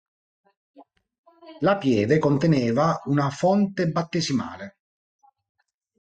Pronounced as (IPA) /ˈfon.te/